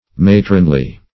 Matronly \Ma"tron*ly\, a.